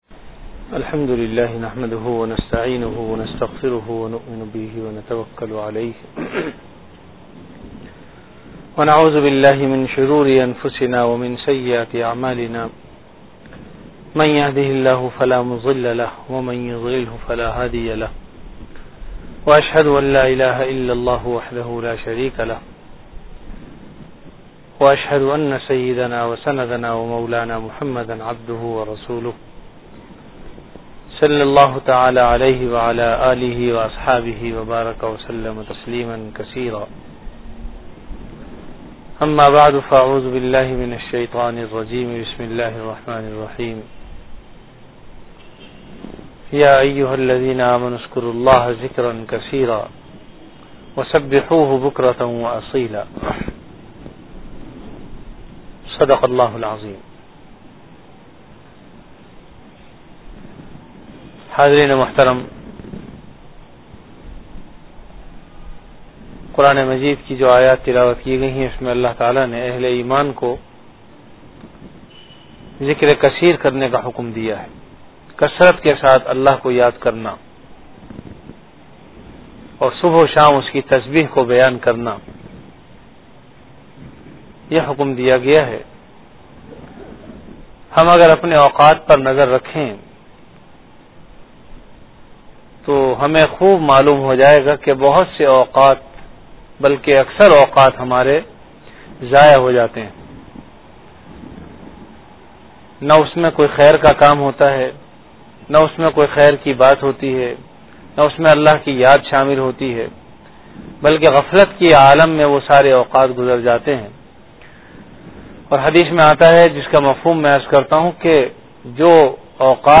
Bayanat · Jamia Masjid Bait-ul-Mukkaram, Karachi
After Isha Prayer